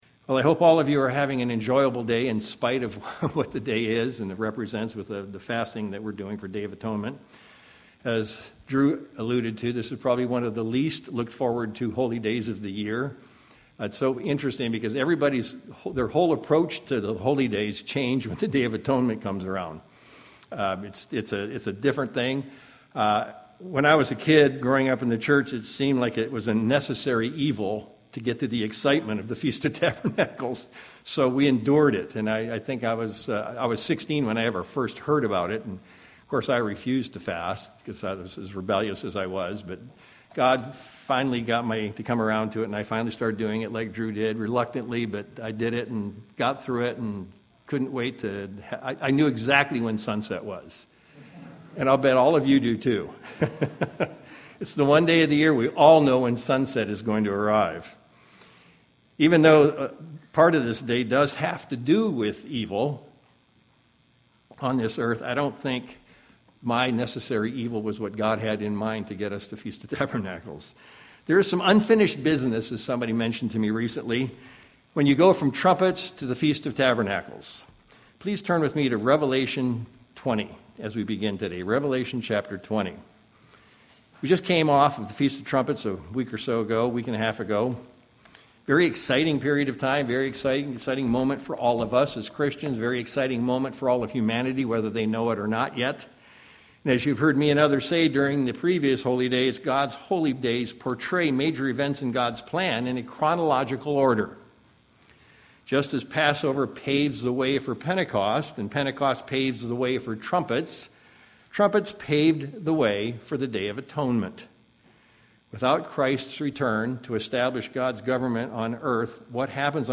Webcast Sermons
Given in Sacramento, CA